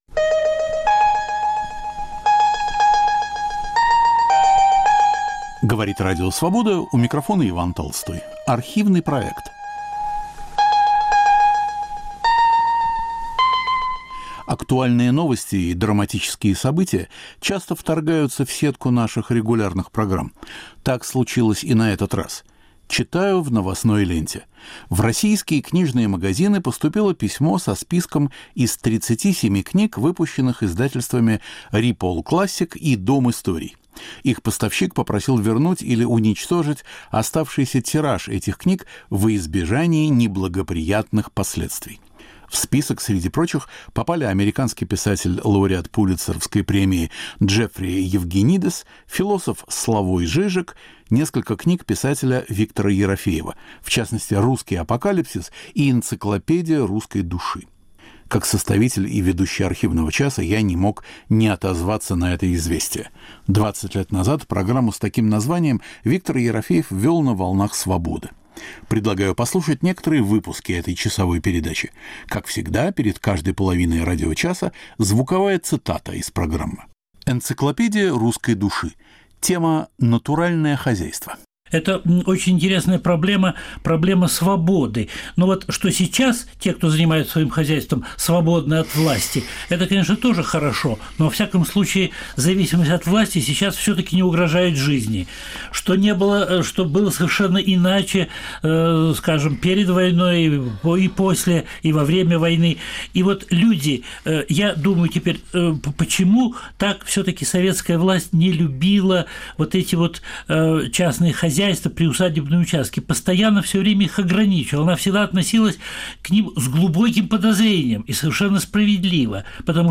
Автор и ведущий Виктор Ерофеев.